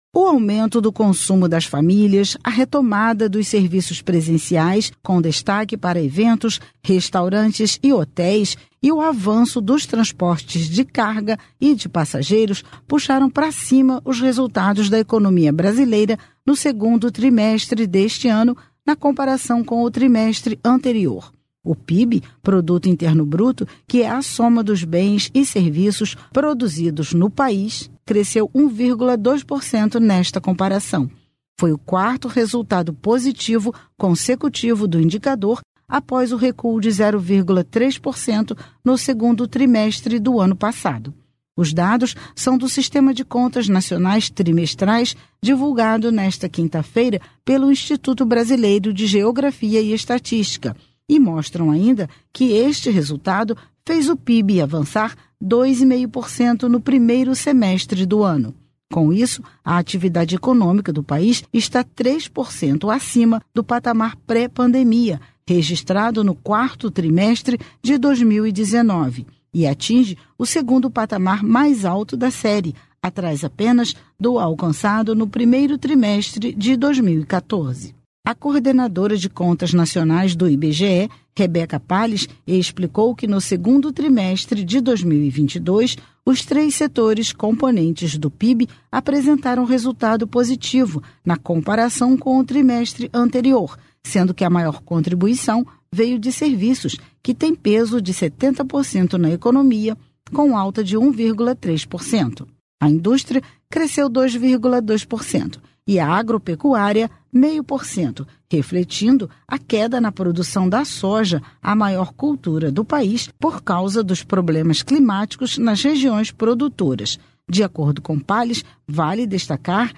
Rio de Janeiro